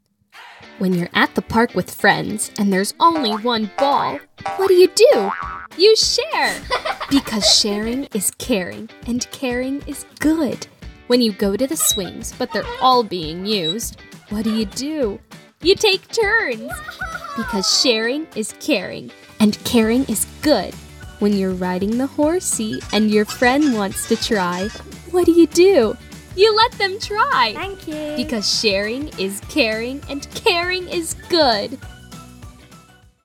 Demos
Sharing Is Caring, Kids:Fun:Educational
Southern (American)
My timbre is youthful and bright with a clear and direct expression.